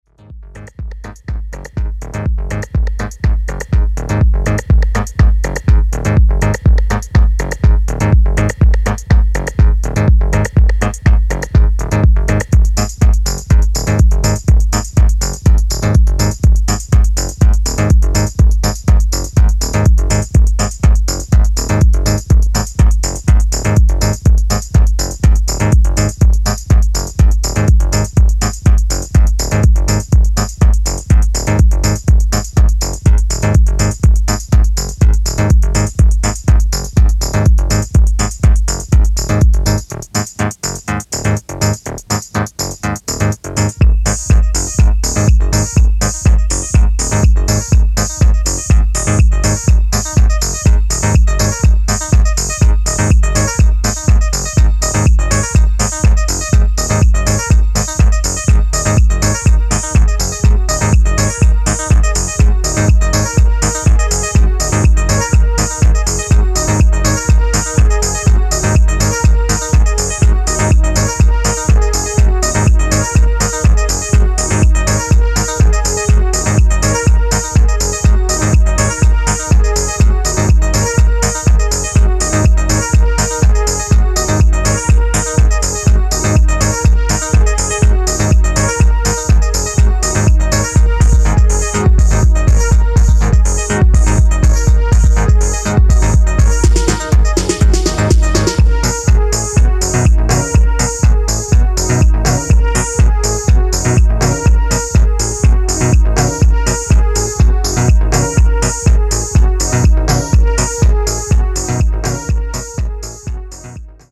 催眠的ミニマルリフ、畳み掛けるスネアによる素朴にしてファンキー